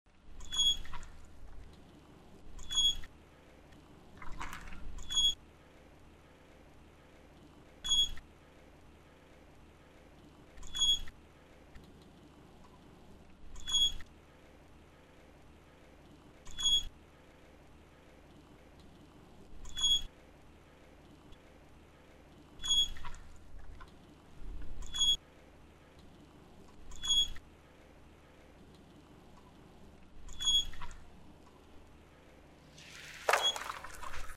Звук качелей
Качель тоскливо попискивает, когда кто-то сидит и тихонько качается, потом человек спрыгивает
tosklivyj-zvuk.mp3